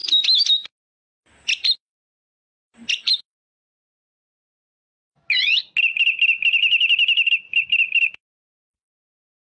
黄雀压油葫芦口
黄雀压油葫芦口。